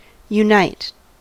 unite: Wikimedia Commons US English Pronunciations
En-us-unite.WAV